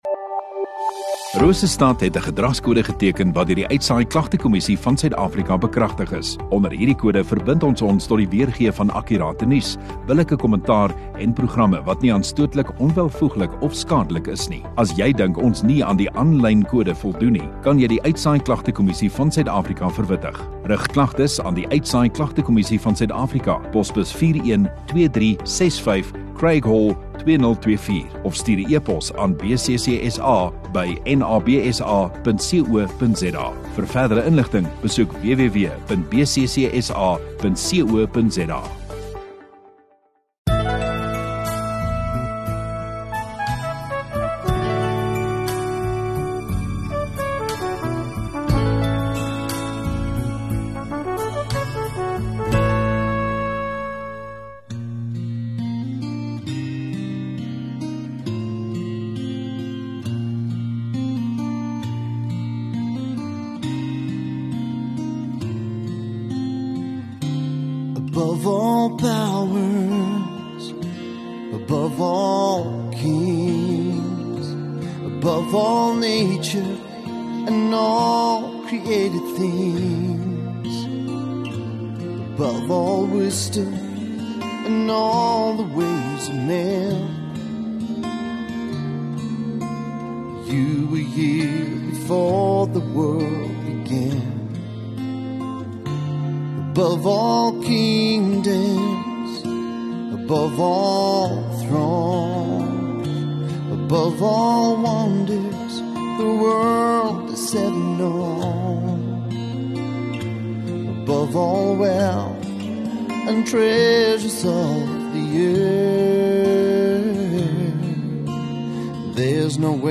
7 Jul Sondagoggend Etediens